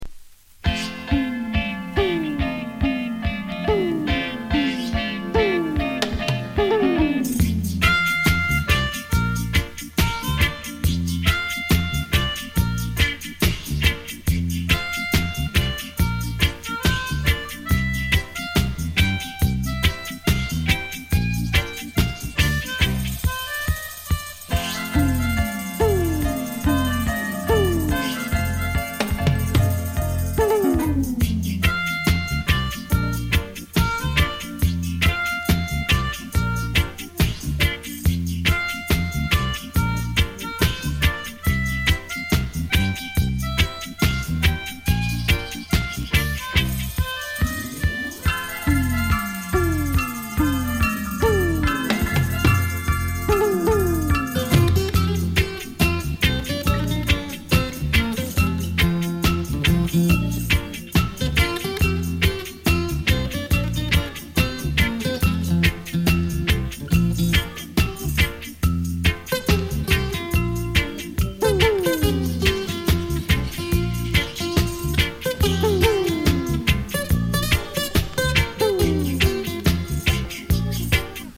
DUB, セサミストリート *
多少 ヒス・ノイズ 乗りますが、曲の間はほぼ気になりません。